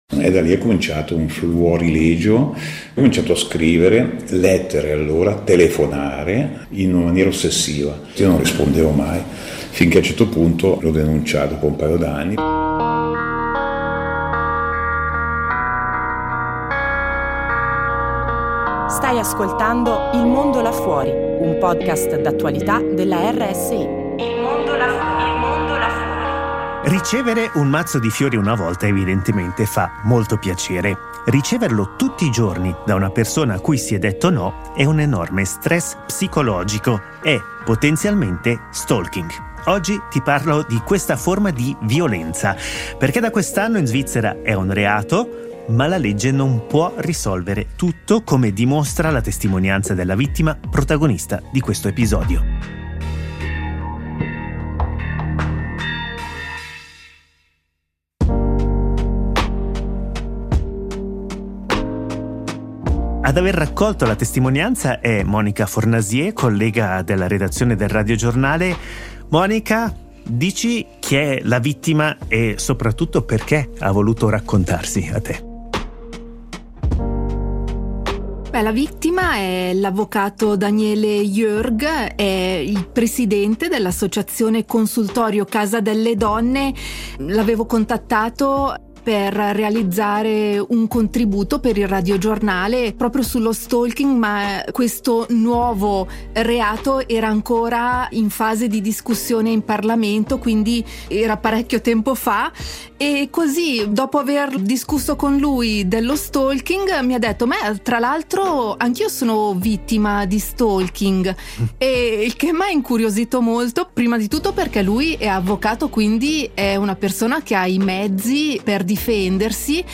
La testimonianza di un uomo ormai rassegnato e riflessioni sulla nuova legge